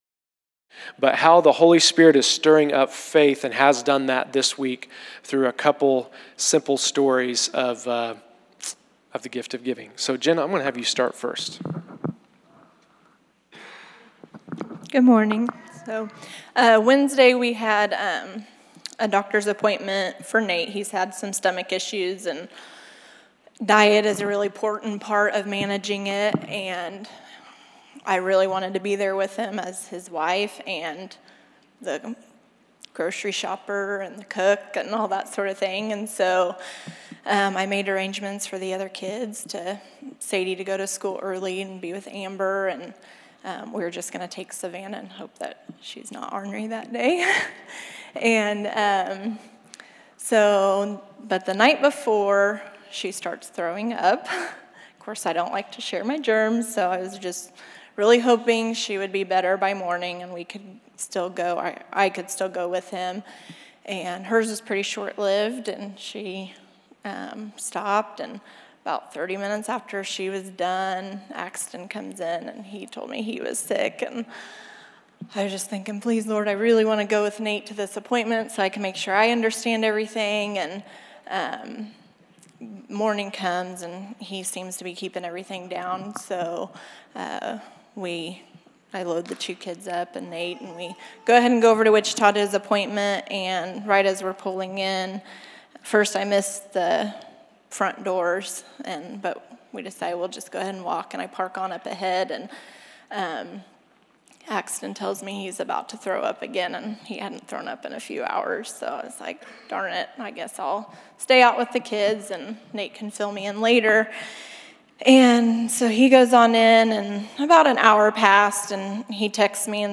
Category: Testimonies      |      Location: El Dorado